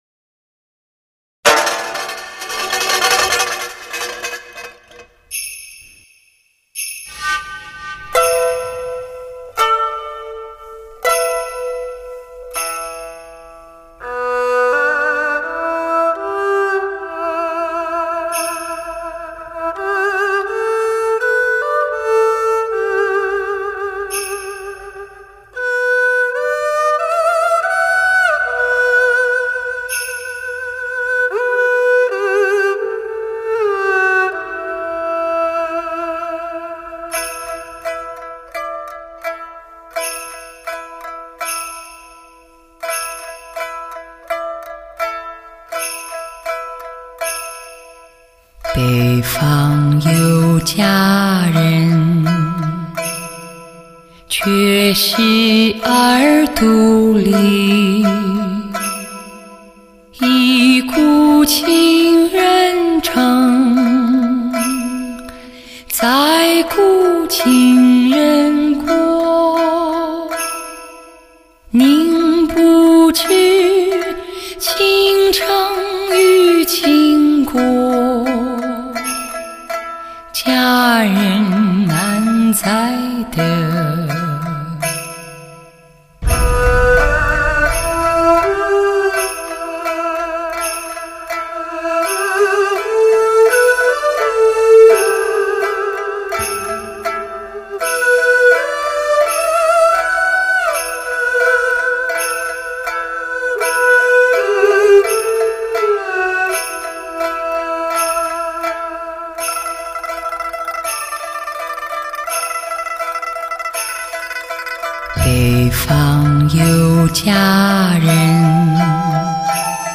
发烧音乐极品发烧人声测试，挑战您的试音概念
沒有龐大樂隊伴奏 有如清唱般的 如此才能聽出演唱者的功力
试音CD的音质就是好，谢谢分享